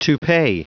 Prononciation du mot toupee en anglais (fichier audio)
Prononciation du mot : toupee